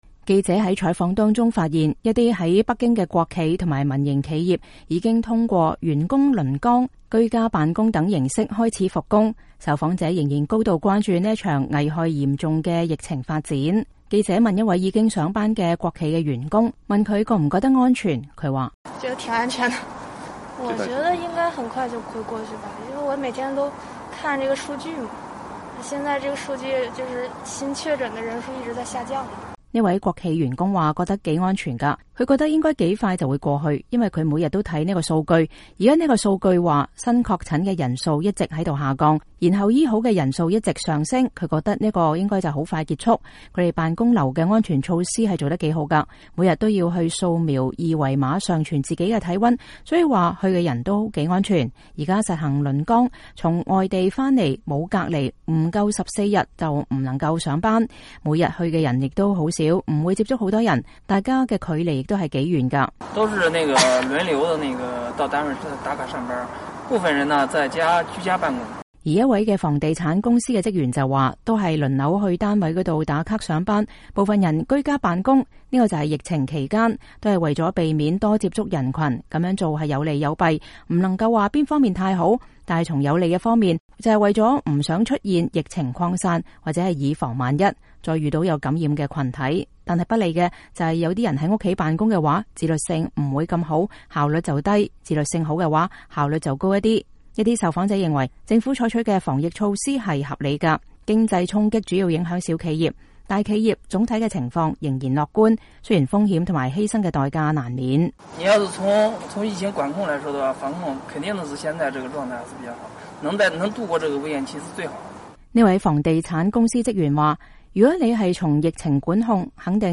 北京街訪: 復工人員談疫情影響及風險
美國之音記者日前在北京市區隨機採訪了一些復工人員和恢復營業的商家。